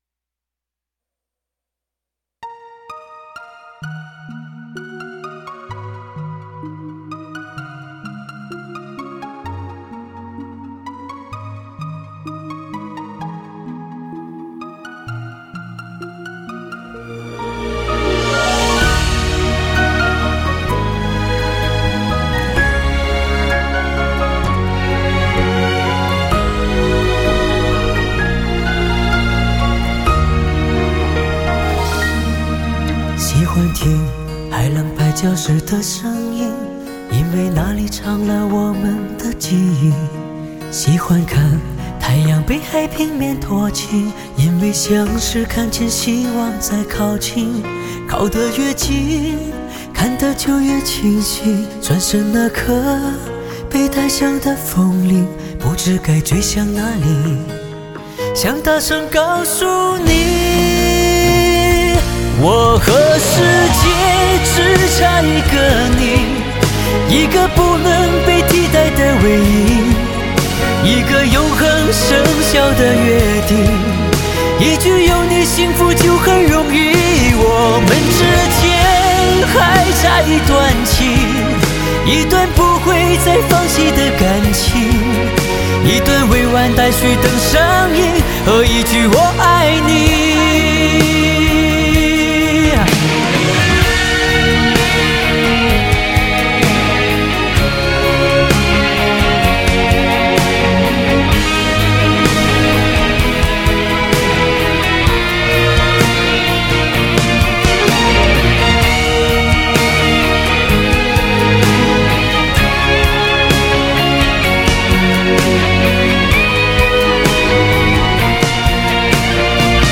整张专辑以情歌为篇章，每听一曲彷如翻开一页故事。